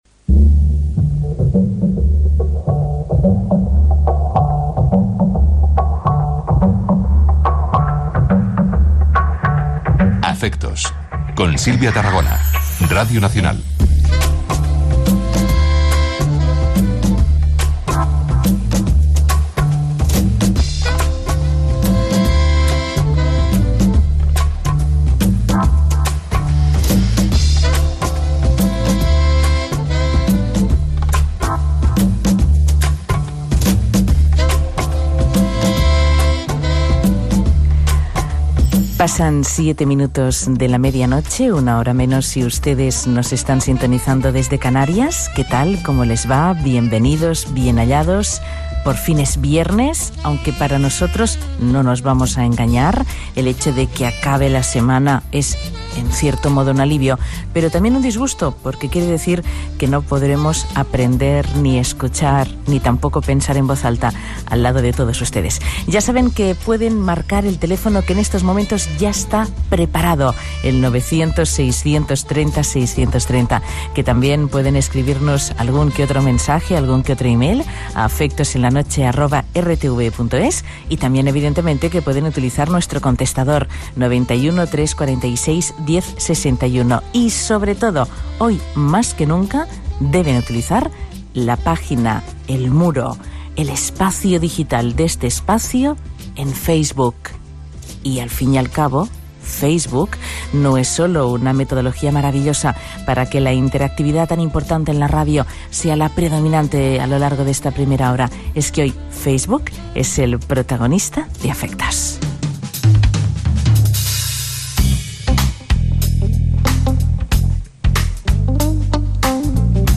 Careta del programa, hora, presentació, formes de contactar amb el programa, equip i espai dedicat a la xarxa social Facebook Gènere radiofònic Entreteniment